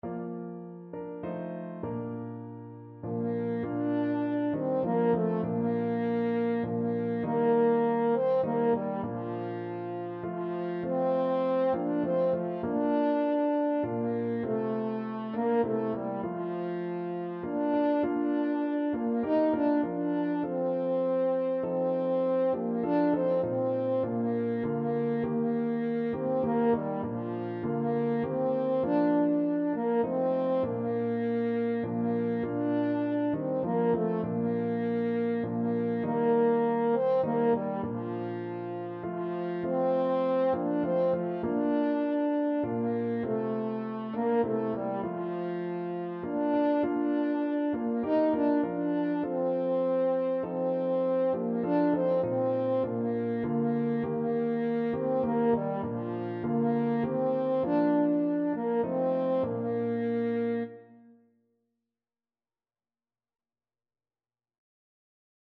3/4 (View more 3/4 Music)
F4-Eb5
Classical (View more Classical French Horn Music)